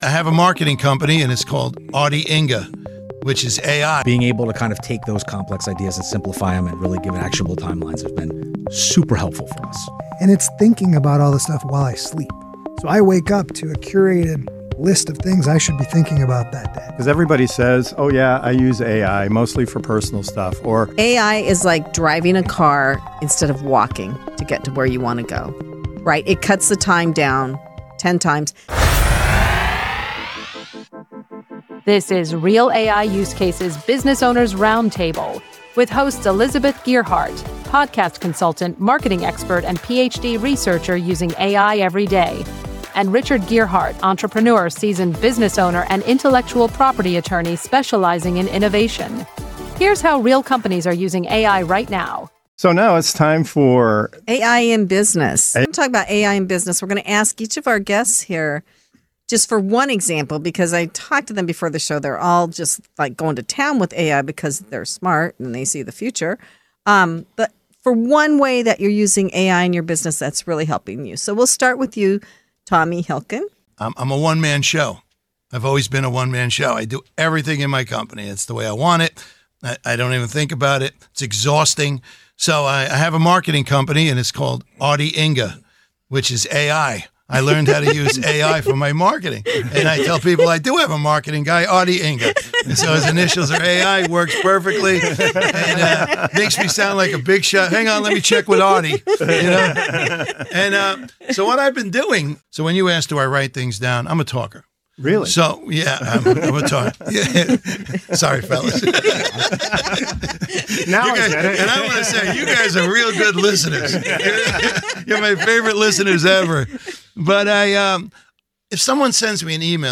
Real AI Use Cases Business Owners Roundtable How Does AI Give Business Owners More Time?